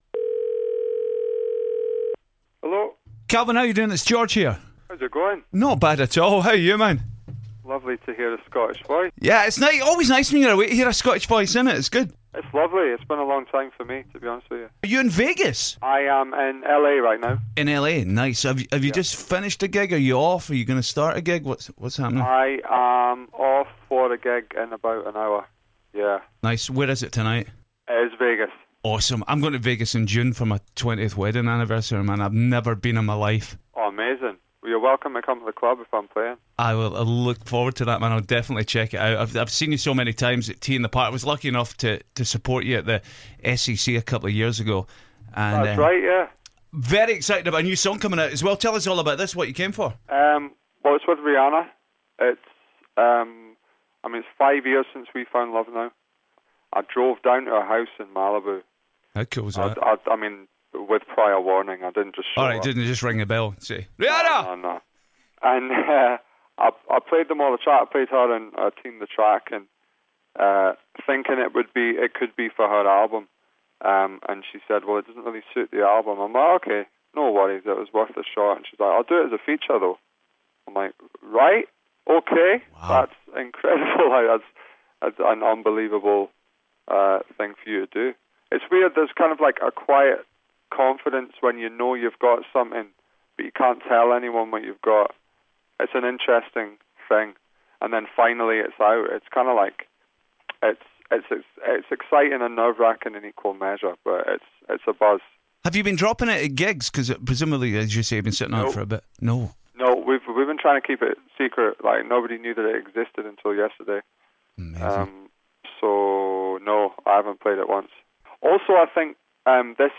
Calvin Harris chatting exclusively on Clyde 1's GBX